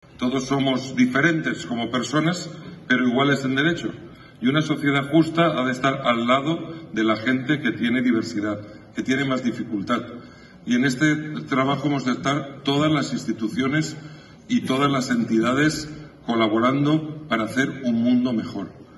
defendió en su intervención Enric Morera formato MP3 audio(0,72 MB).